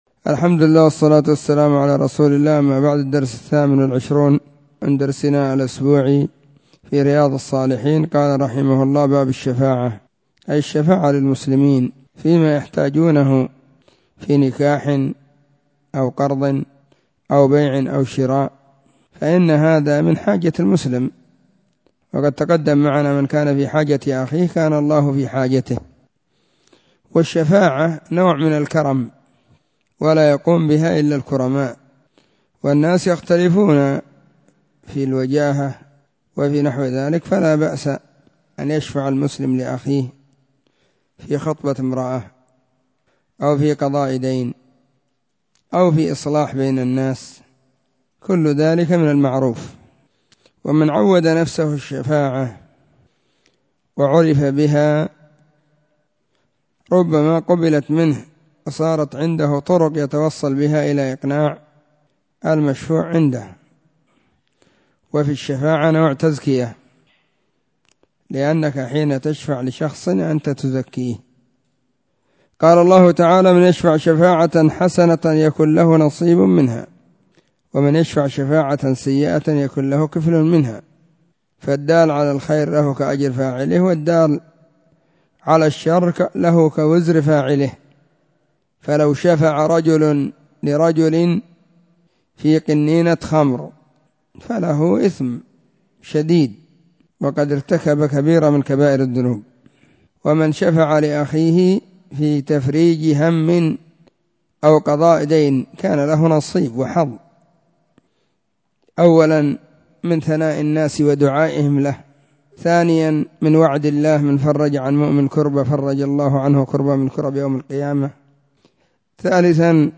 🕐 [بين المغرب والعشاء في كل يوم الخميس]
🕐 [بين المغرب والعشاء في كل يوم الخميس] 📢 مسجد الصحابة – بالغيضة – المهرة، اليمن حرسها الله.